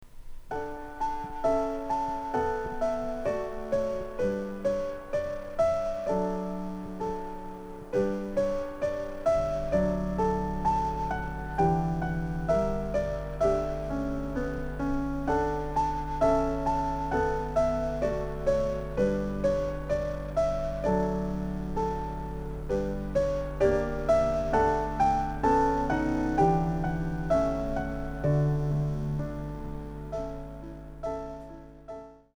どうにも音がおかしいんですよ
ピアノベースのしっとり曲になって
明らかに音がビビってました＝■●＿
生録音声